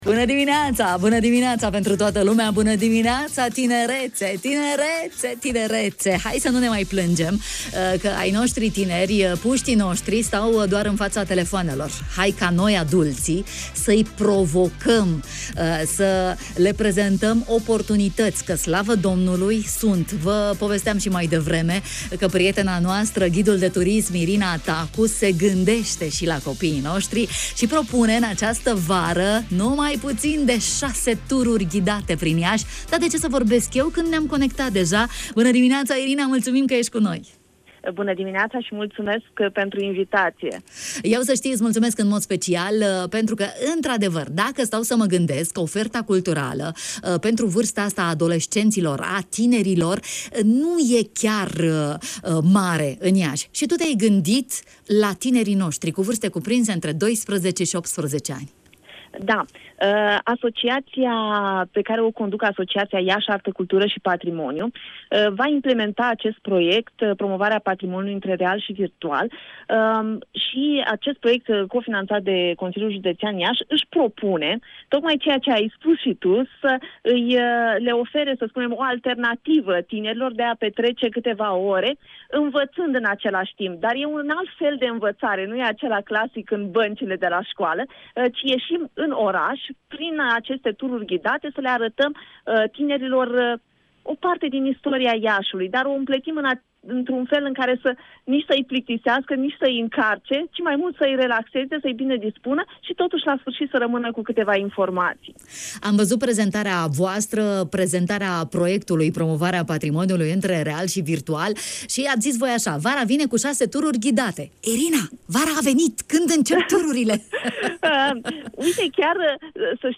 în direct la matinalul de la Radio Iași: